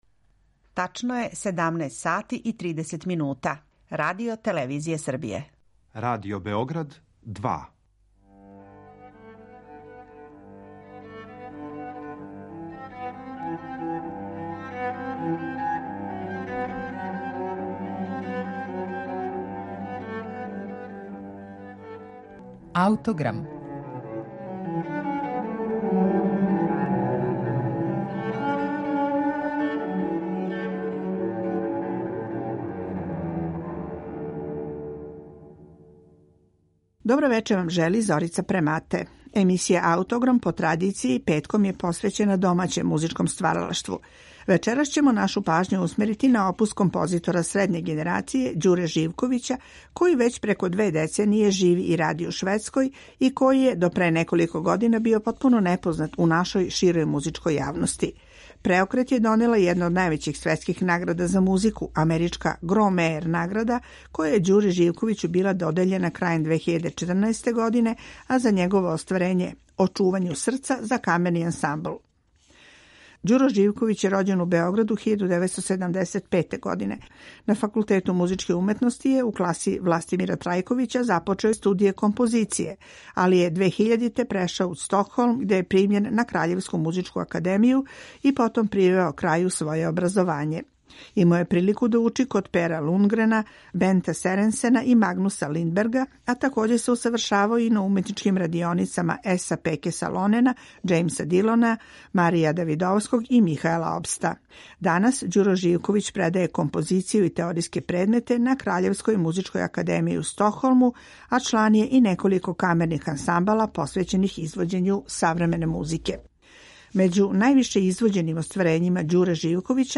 Обележје његовог стила је прожимање елемената најстаријих слојева етничке и духовне музике Балкана са савременим музичким изразом.